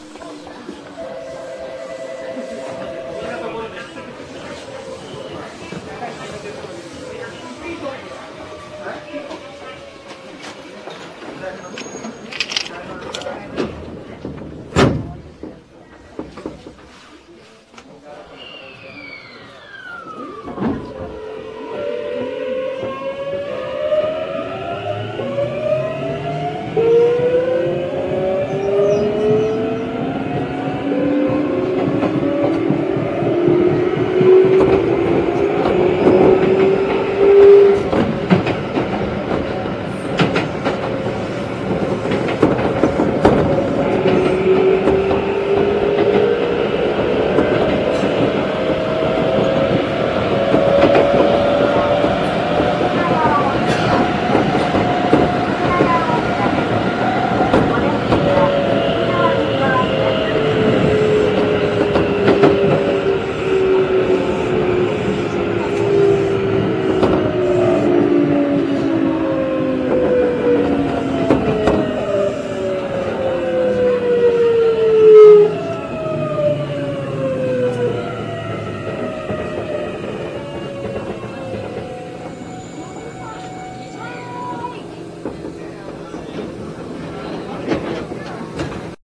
東洋ＧＴＯ後期と初期を足したような音です。
走行音（7802）
収録区間：池上線 石川台→雪が谷大塚